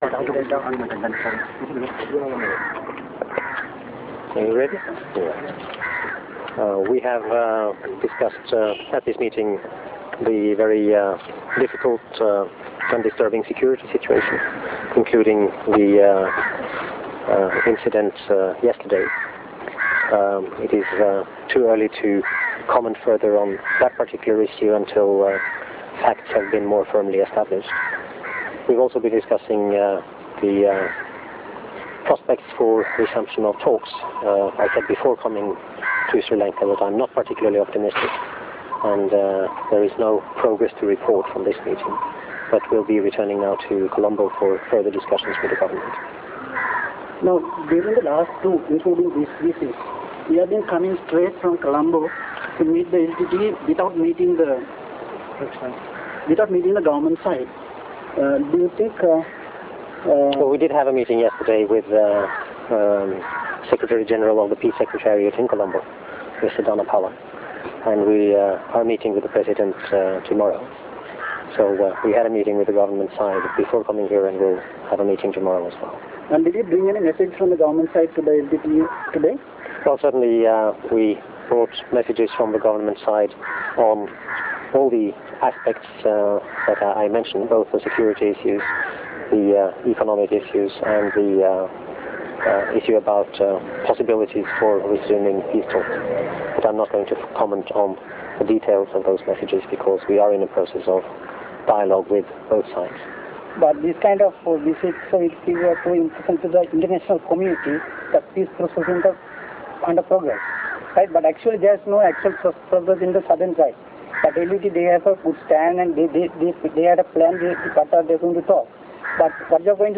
Real Audio Icon Interview with Vidar Helgesen